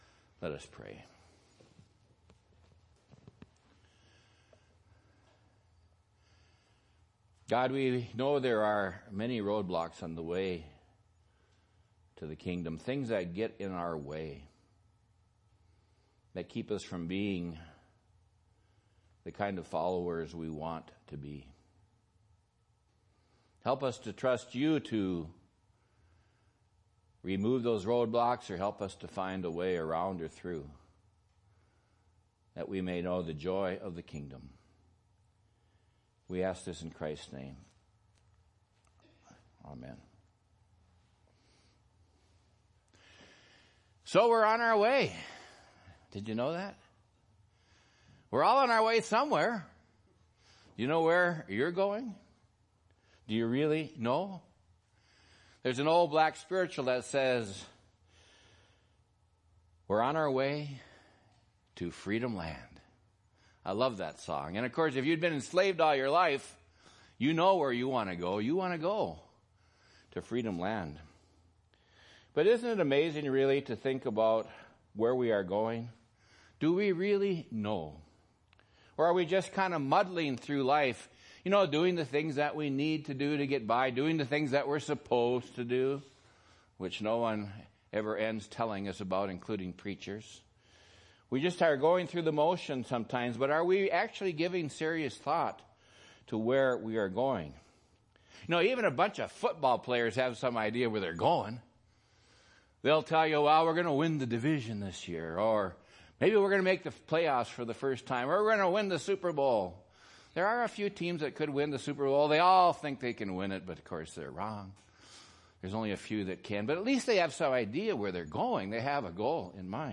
Sermon (October 11, 2015): Roadblocks to the Kingdom